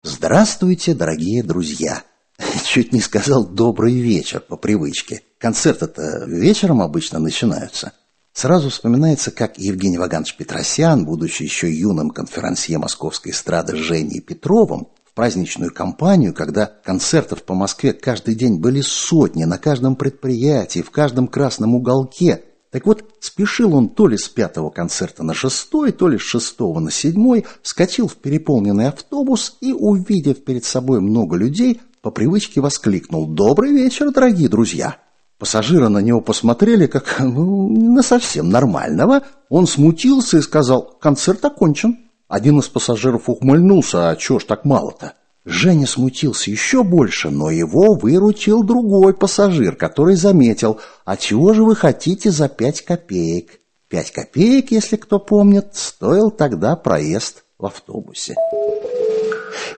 Аудиокнига Актёрские байки | Библиотека аудиокниг